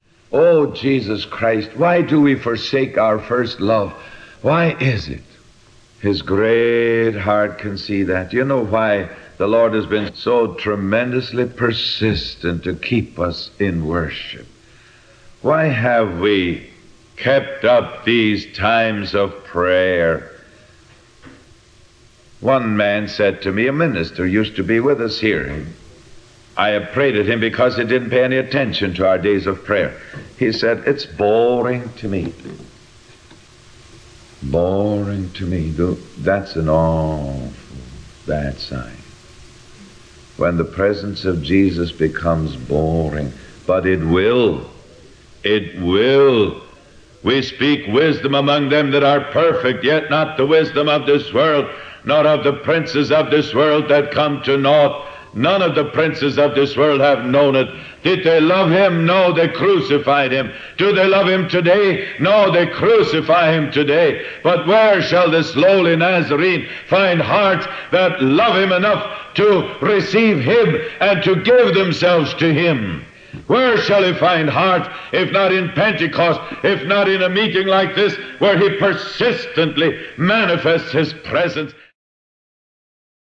Audio Quality: Good